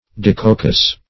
Meaning of dicoccous. dicoccous synonyms, pronunciation, spelling and more from Free Dictionary.